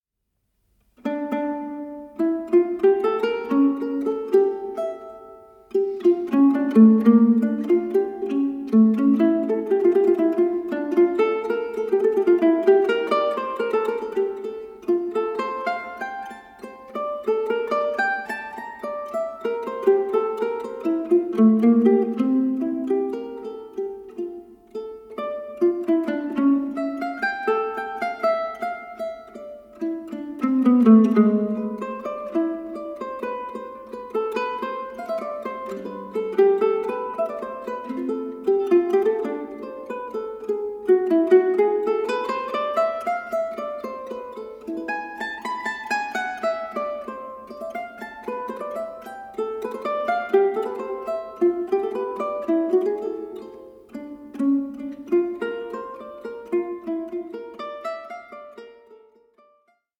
tuscan mandolin